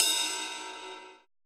RX RIDE.wav